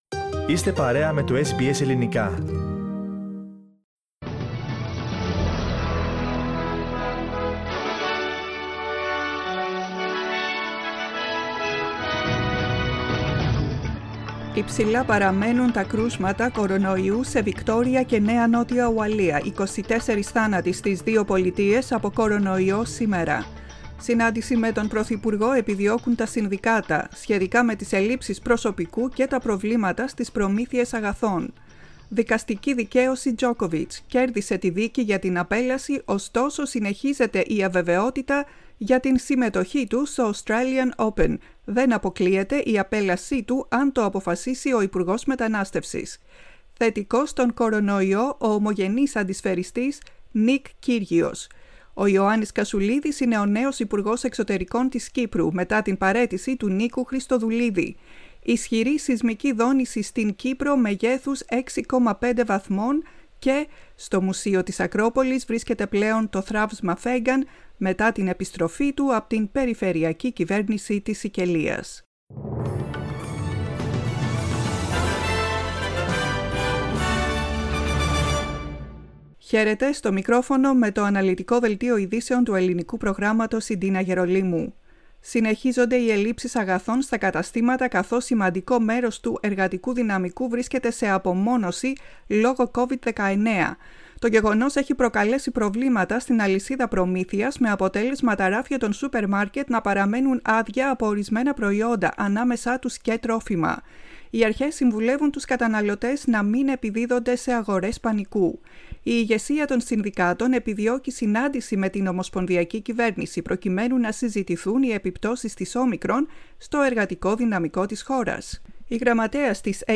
Listen to the main bulletin of the day in Greek.